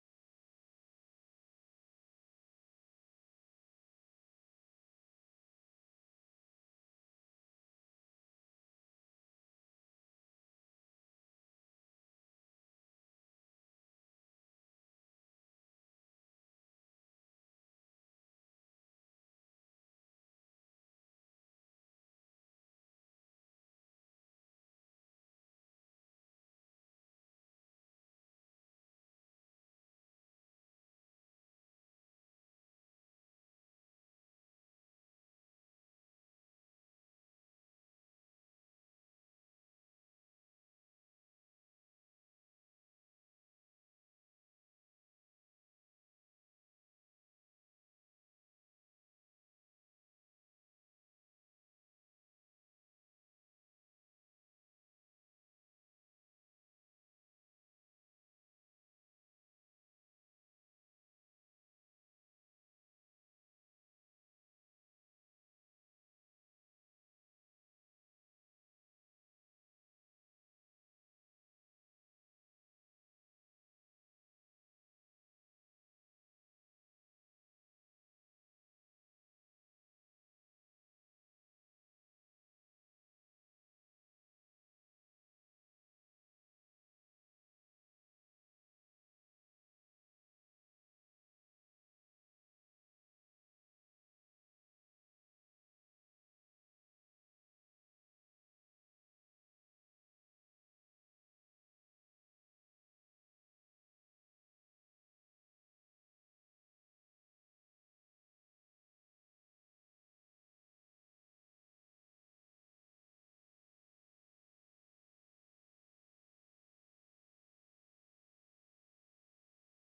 【解決策②】無音を流し続ける ※英語部イチオシ！
Sunshine英語部で、60分の無音が再生される音声ファイルをご用意しました。
no_sound.mp3